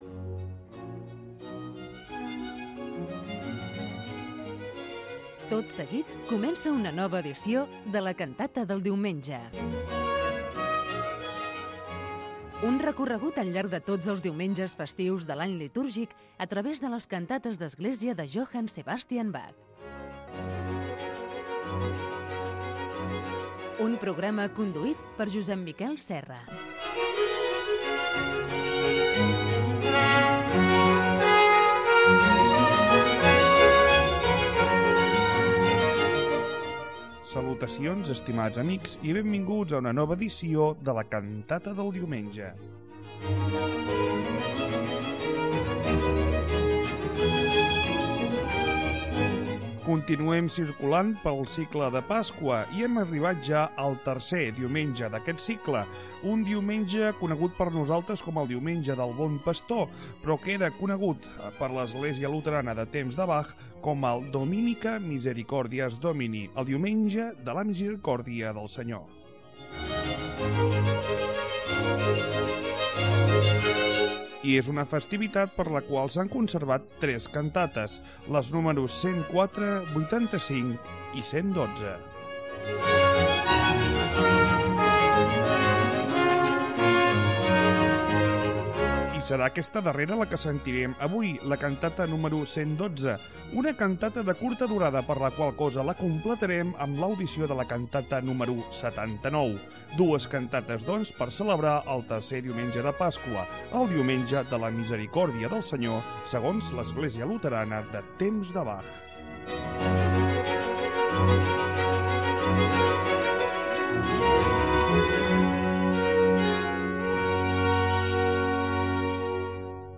Audició d’una Cantata de Johann Sebastian Bach, destinada al Diumenge corresponent del calendari luterà